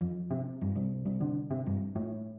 Chello Bass 80 Bpm F
描述：很好的古典低音弹拨，用果味循环制作，如果你使用它，请告诉我。
Tag: 80 bpm Classical Loops Strings Loops 413.61 KB wav Key : F